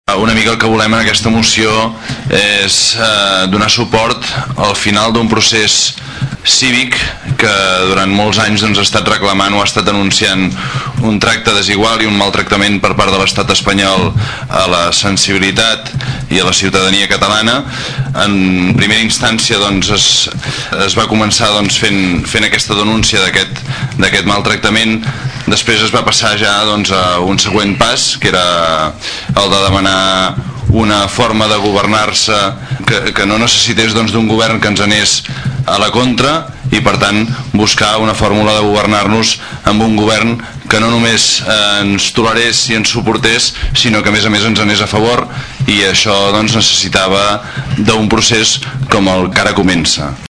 El regidor de CiU, Josep Llorens, recordava que s’ha arribat fins aquí després de diverses mobilitzacions, les quals han acabat amb unes eleccions extraordinàries que han deixat un Parlament més independentista que mai.